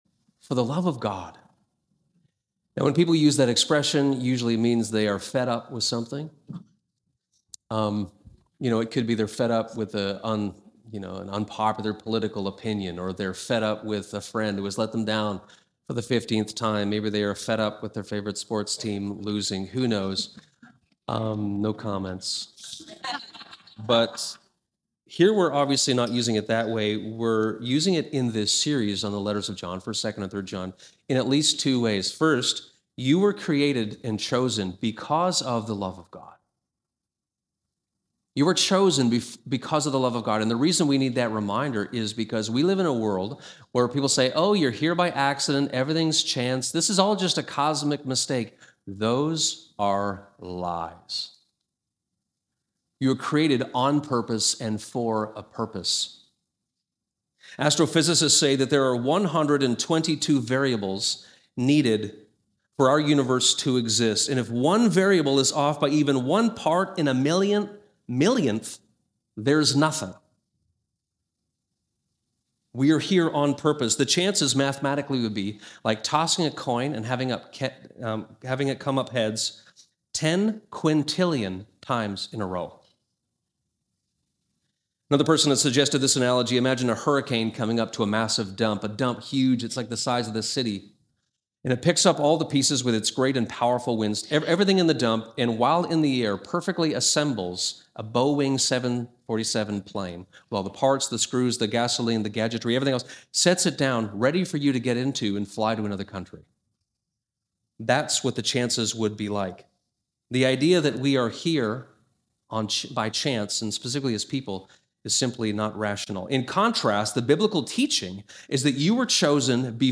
Sermons | Westminster